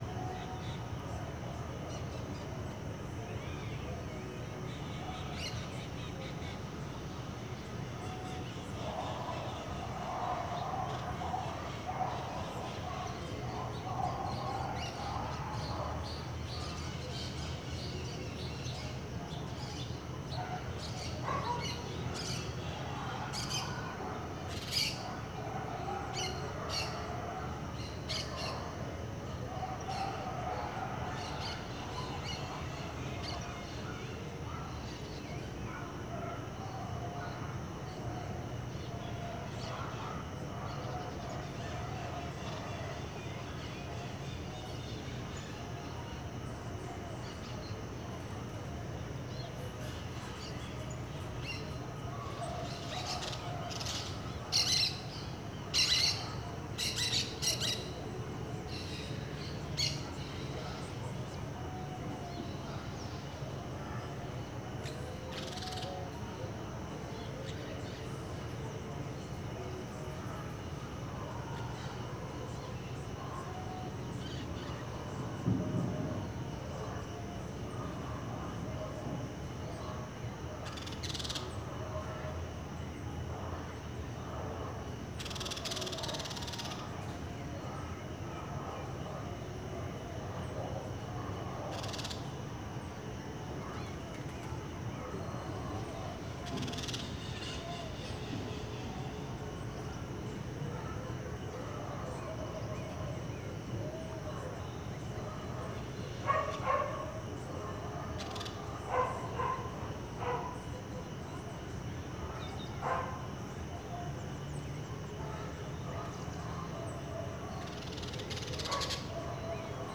CSC-04-193-LE - Ambiencia residencial goiania em fim de tarde com passaros, cachorros brigando, musica e grilos.wav